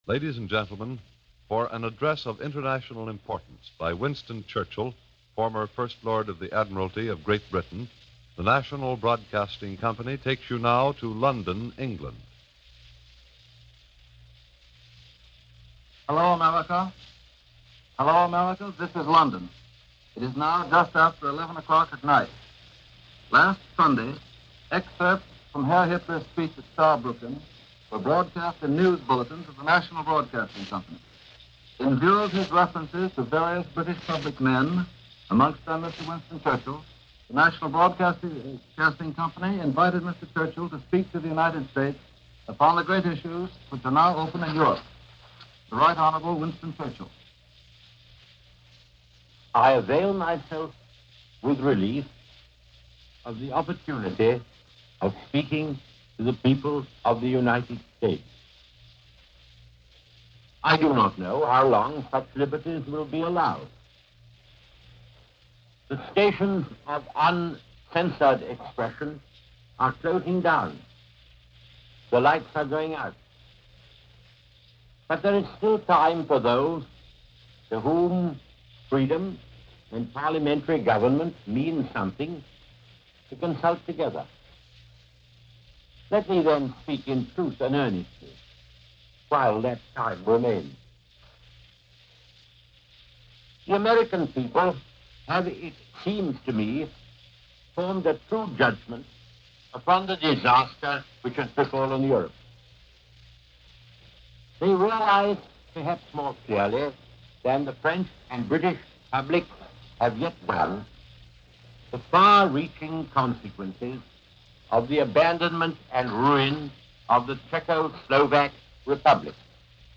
On October 16, 1938, Member of Parliament Winston Churchill broadcast directly to the United States. The speech is an impassioned appeal for greater American involvement in Europe in the wake of the Munich Crisis and the issue of appeasement to Adolf Hitler for peace in the region.